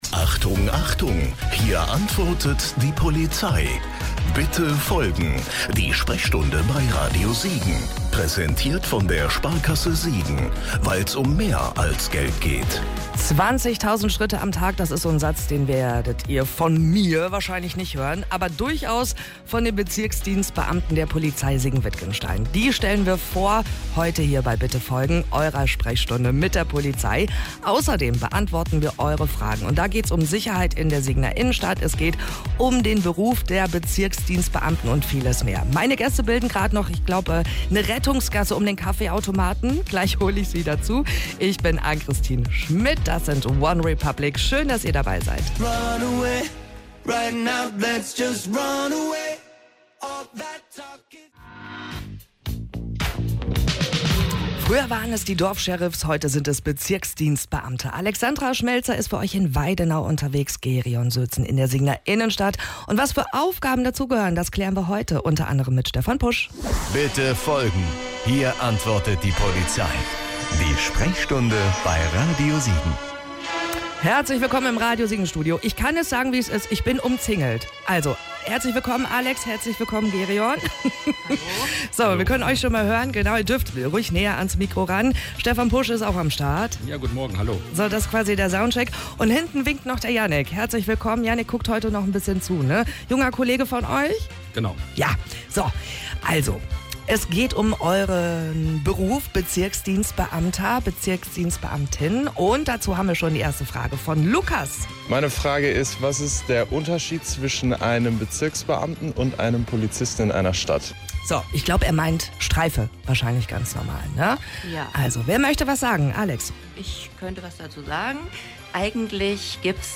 Die März-Ausgabe unserer Polizei-Sprechstunde, dieses Mal mit Beamten aus dem Bezirksdienst, also also von "mittendrin"! - und Fragen zur Sicherheit vor Ort.